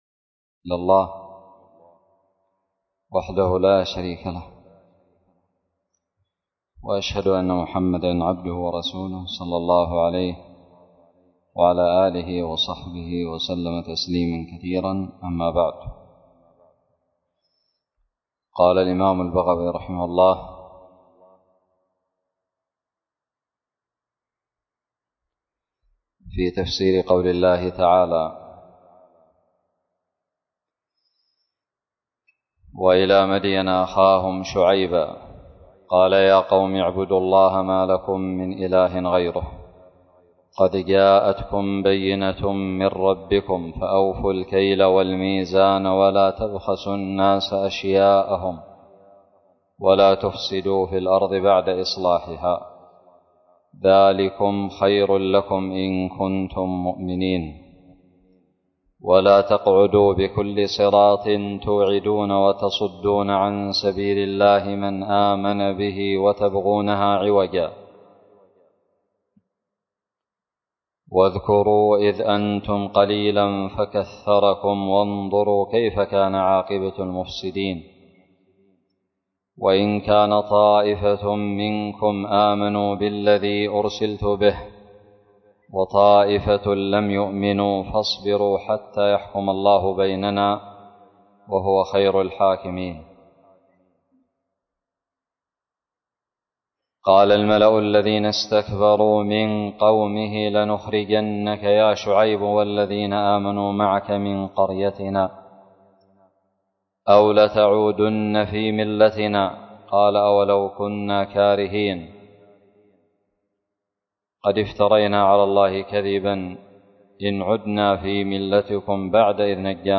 الدرس الحادي والعشرون من تفسير سورة الأعراف من تفسير البغوي
ألقيت بدار الحديث السلفية للعلوم الشرعية بالضالع